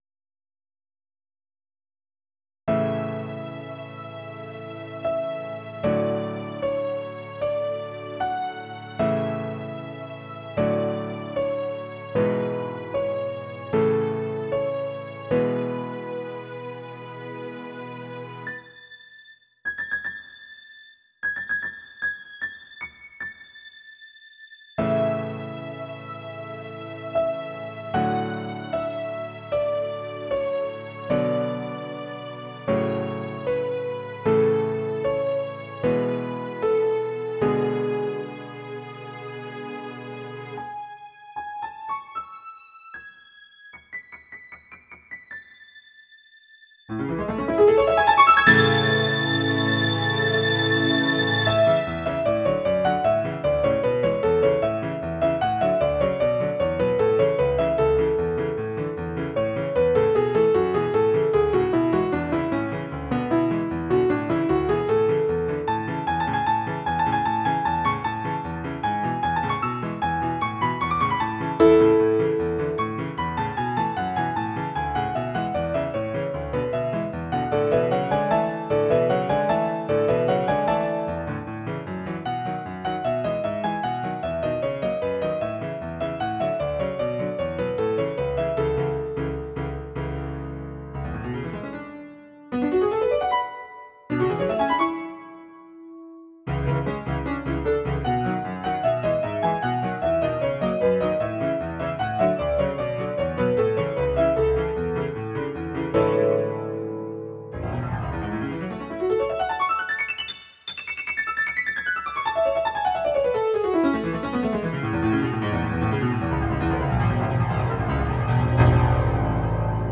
音源は全てVSC-88です。
緩ー急ー緩の3部形式です。